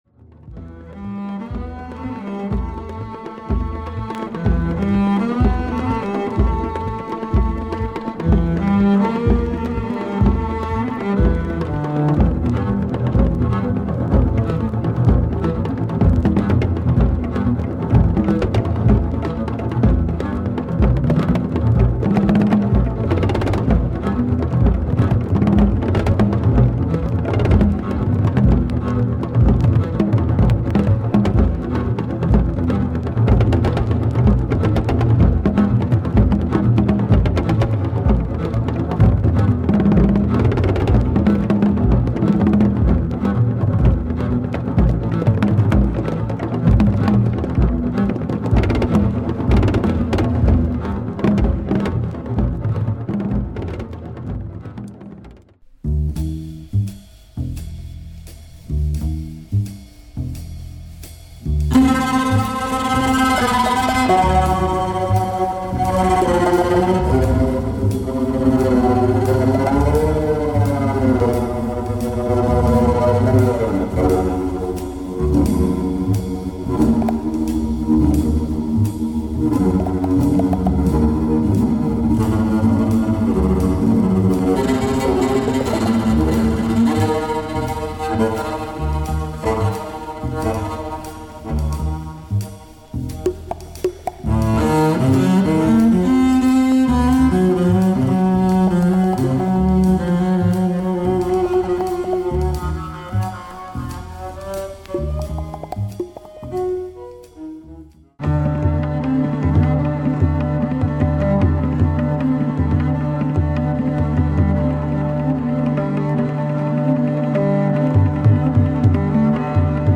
Contemporary jazz and epic wooden bass solos
Deep sounds !